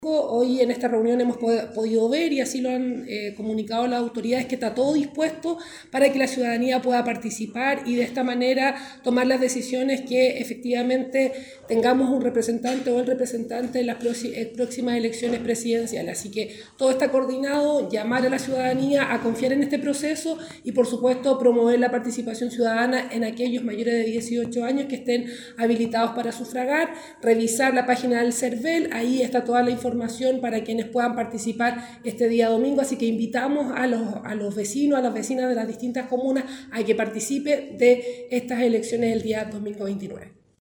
Por último, la seremi de Gobierno, Jacqueline Cárdenas, mencionó que “hemos tratado de llegar a la ciudadanía con la campaña Chile Vota Informado, con el objetivo de promover la participación en torno a las elecciones”.